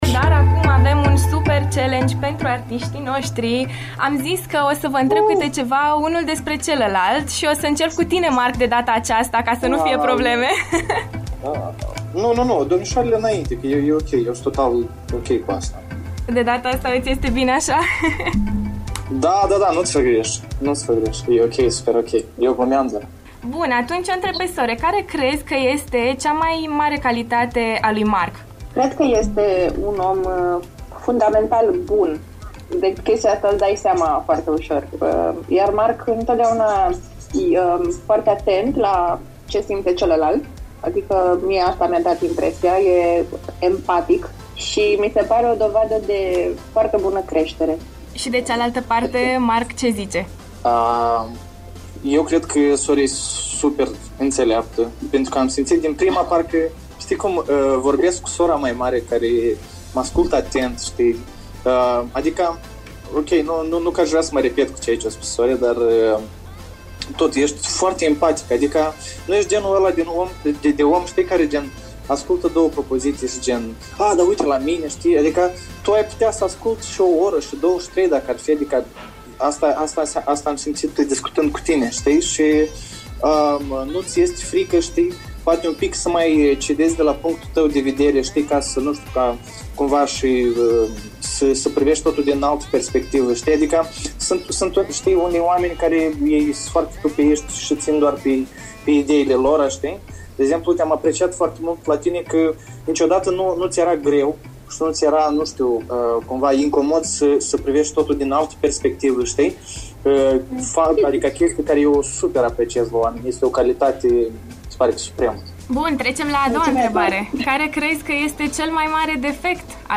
Cât de bine se cunosc Sore și Mark Stam? Challenge LIVE în After Morning